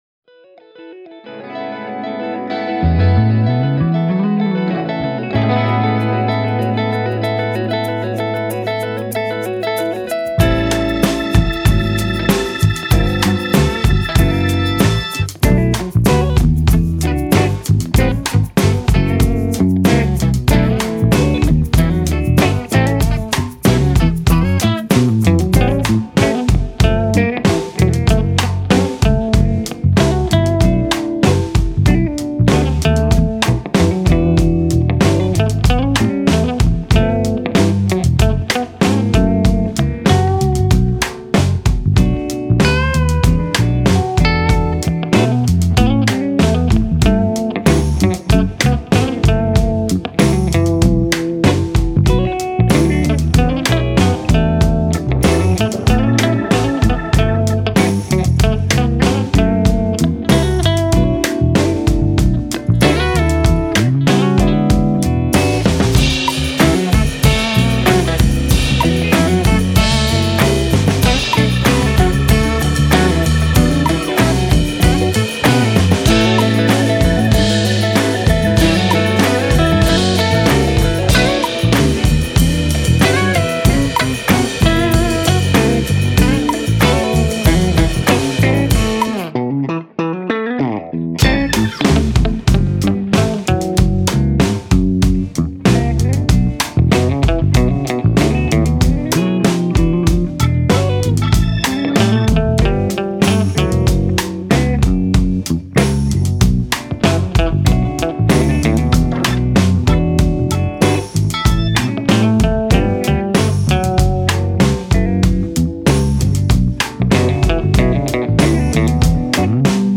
a fresh and exciting R&B & Soul track
blends classic Soul warmth with contemporary R&B flair
Genre: R&B & Soul